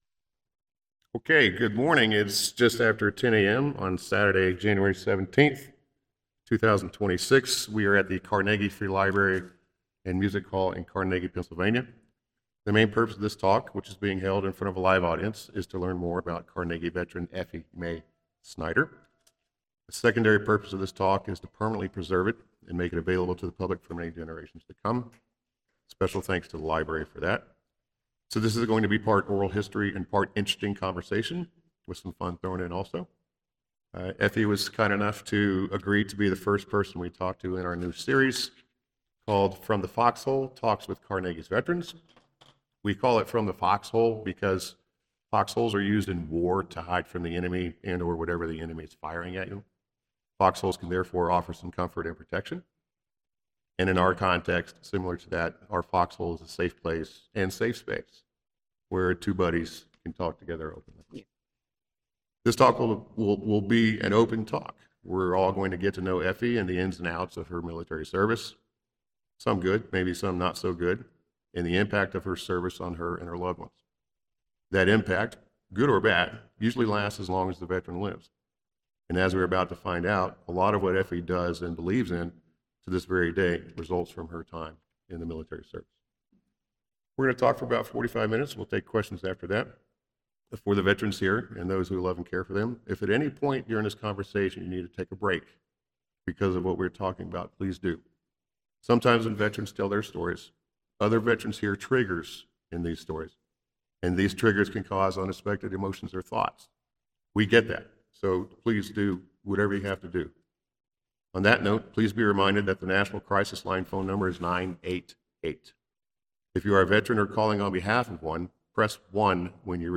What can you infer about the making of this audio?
live, unscripted conversations with the veterans of our area about their experiences while serving